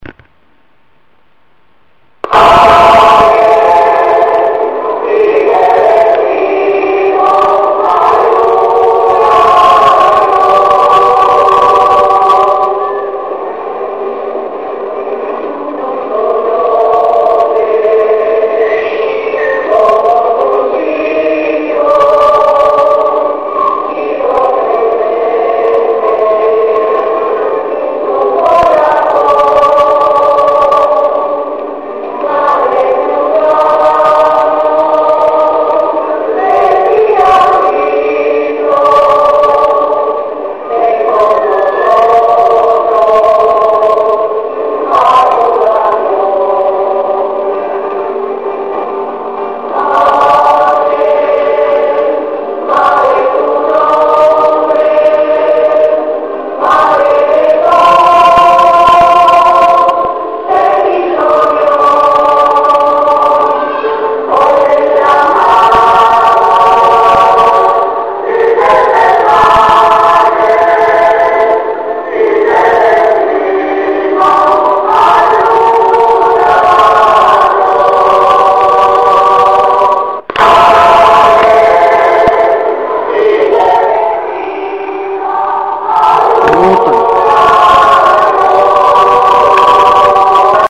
LAS NOVENAS Y ACTOS RELIGIOSOS ESTUVIERON SIEMPRE ACOMPAÑADOS POR LAS VOCES DULCES Y ALEGRES DE LA NIÑAS DEL CORO JUVENIL O POR LAS VOCES MELODIOSAS Y HONDAS DEL CORO DE MAYORES
VALLE2008CORO.MP3